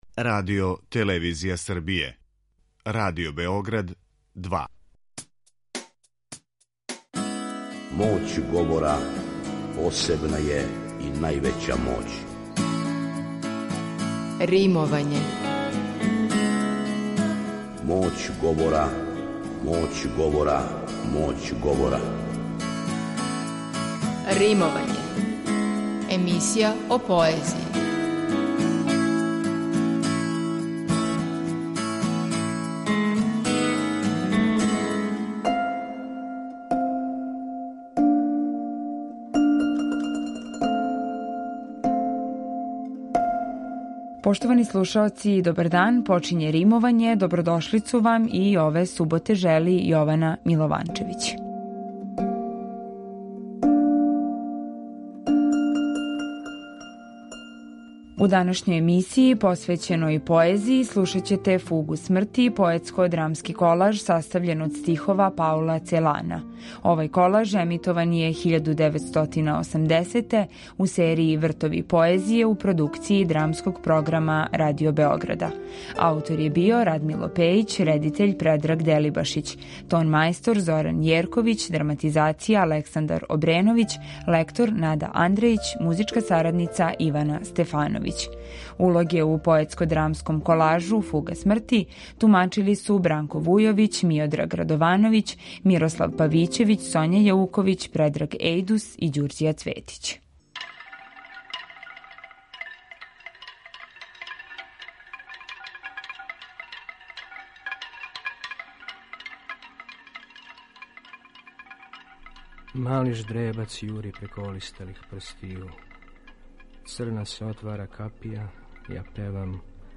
У данашњој емисији посвећеној поезији, слушаћете „Фугу смрти”, поетско-драмски колаж састављен од стихова Паула Целана, који је емитован 1980. године у серији „Вртови поезије” Драмског програма Радио Београда.